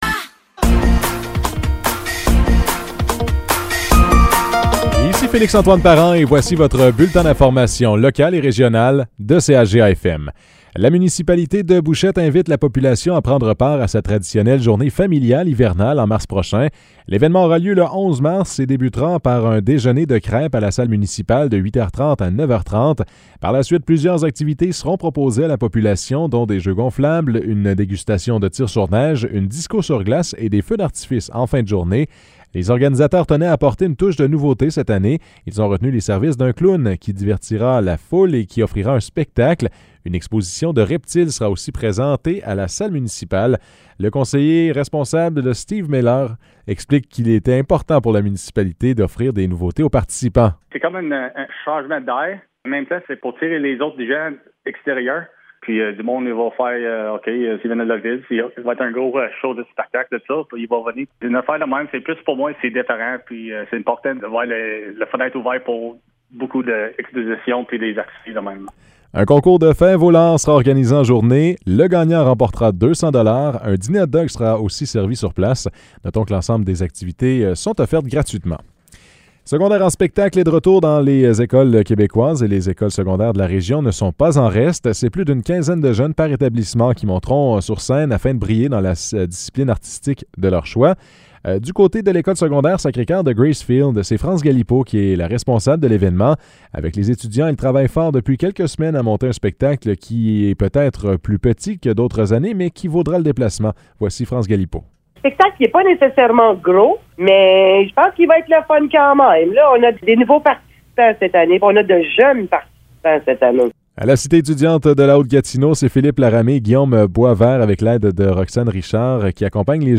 Nouvelles locales - 22 février 2023 - 15 h